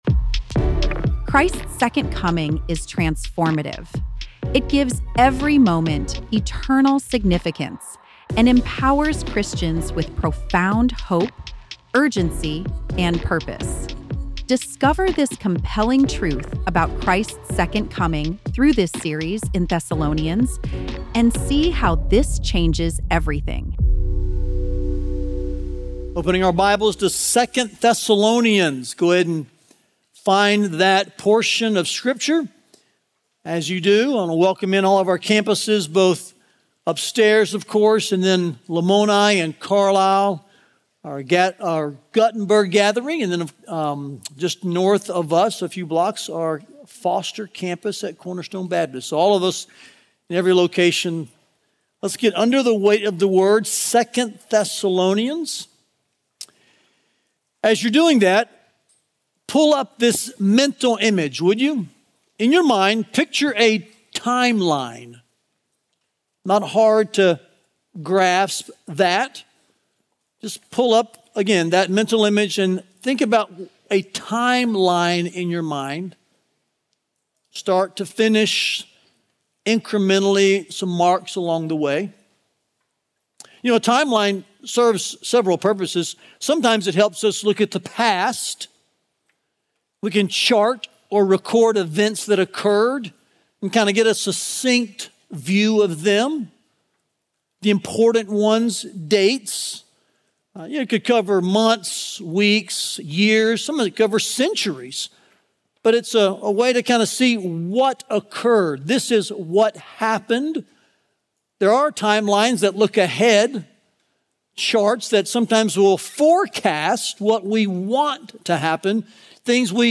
Listen to the latest sermon and learn more about this preaching series here.